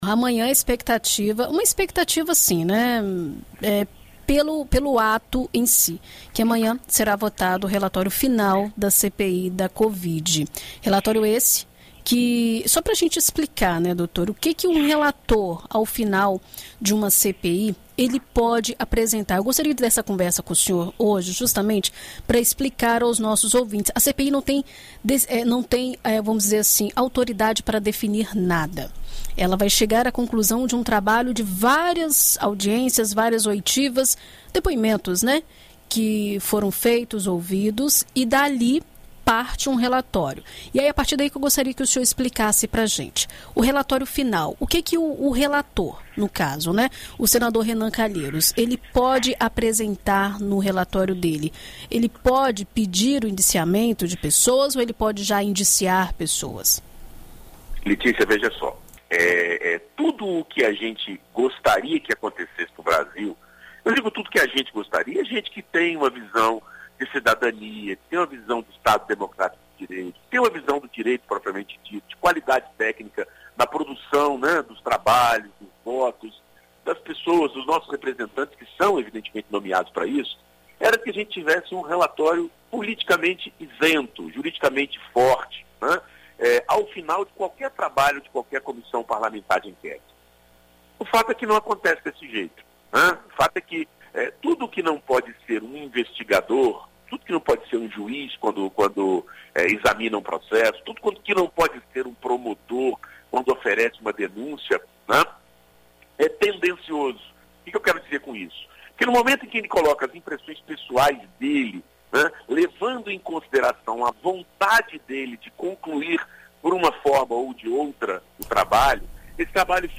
Na BandNews FM